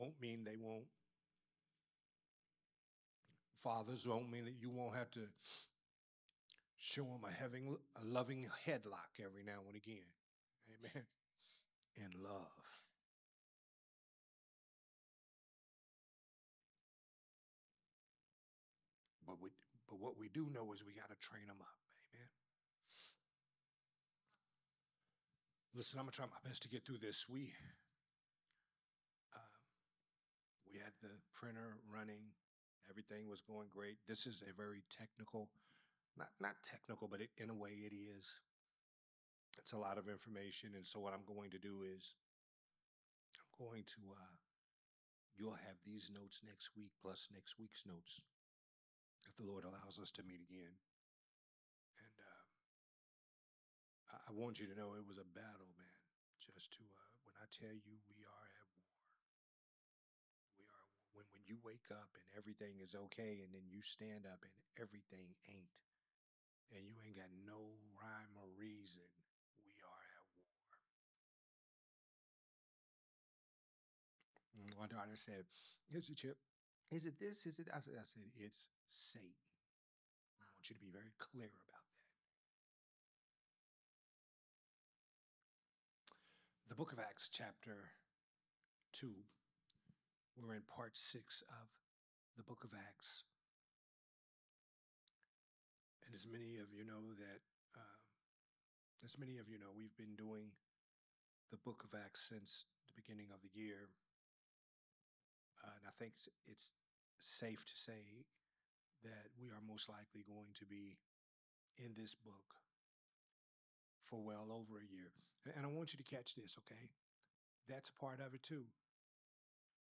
Part 6 of the sermon series
recorded at Growth Temple Ministries on February 15th